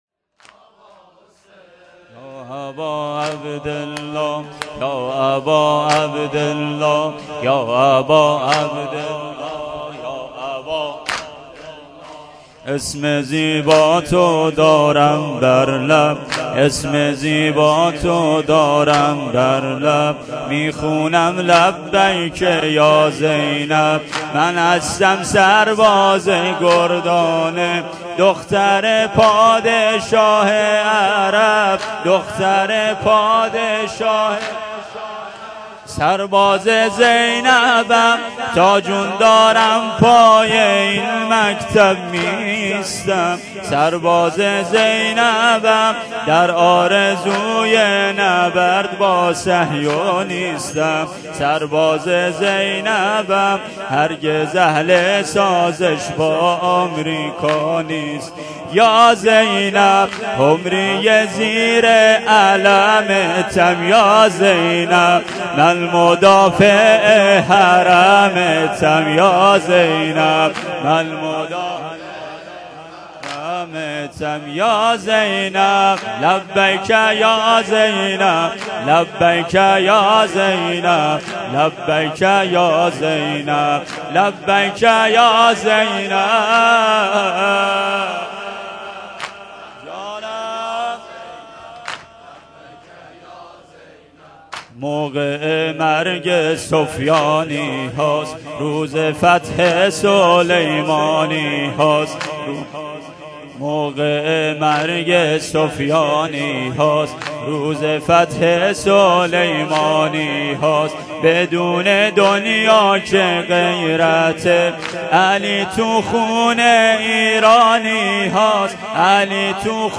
شب سوم محرم الحرام ۹۷